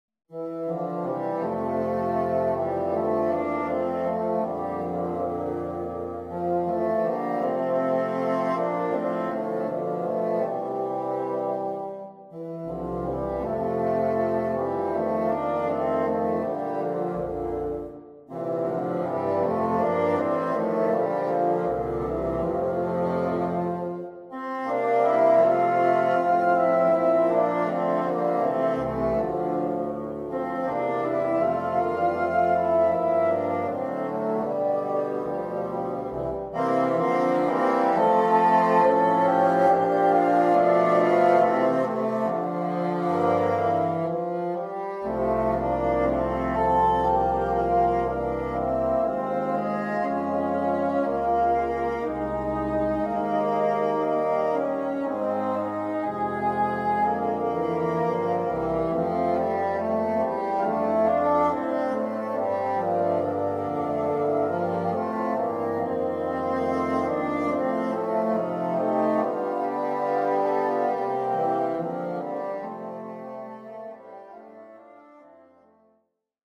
A super arrangement for 4 bassoons